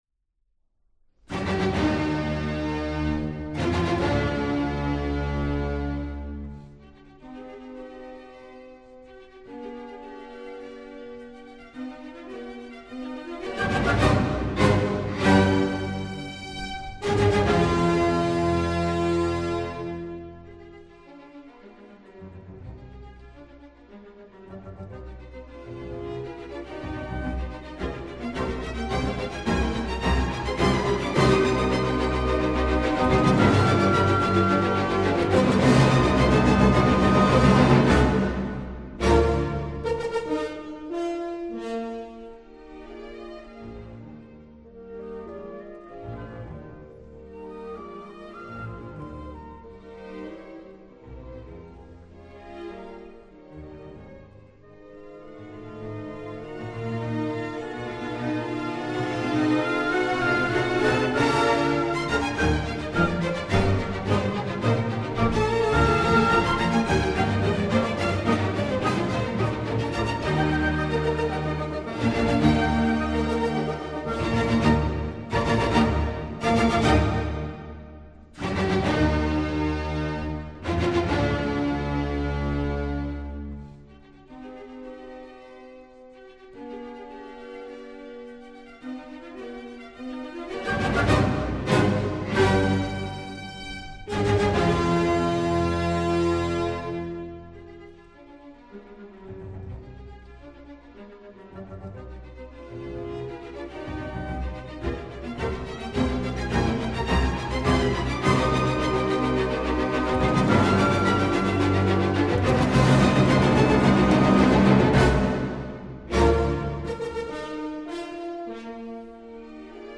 une sorte de mélopée blues
slide guitar